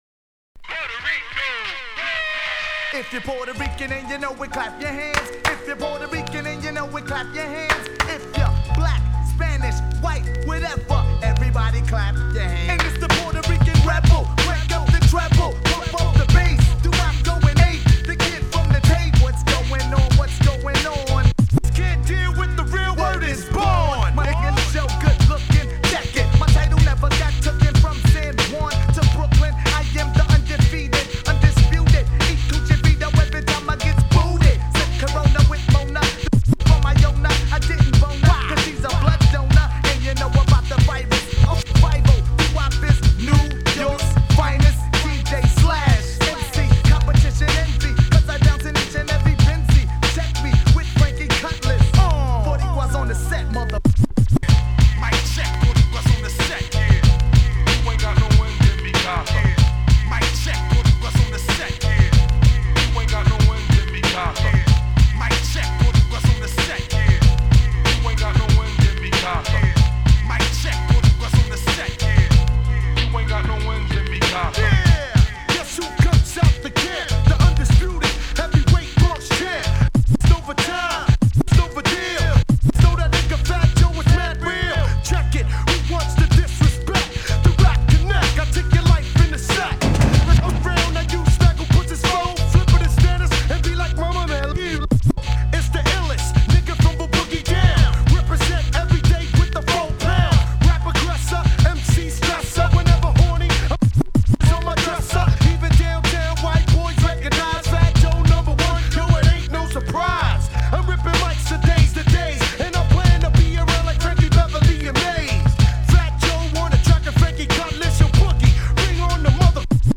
A-1 ORIGINAL RADIO VERSION